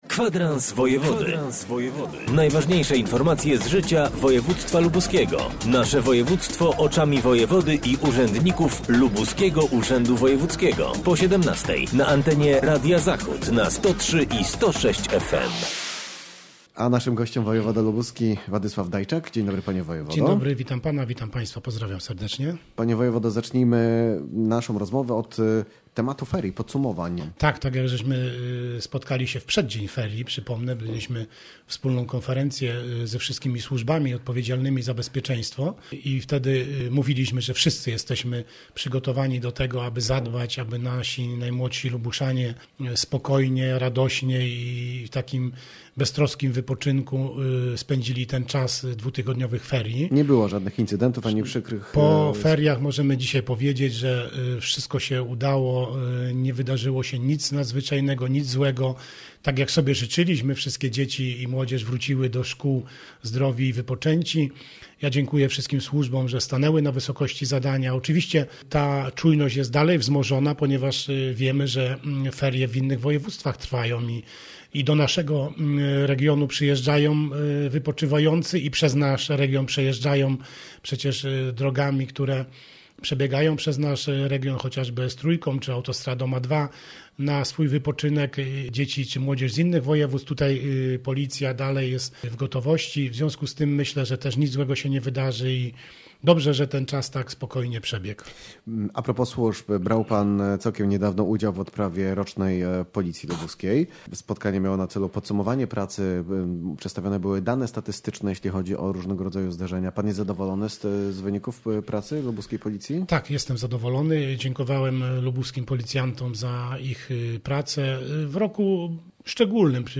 Z wojewodą lubuskim rozmawiał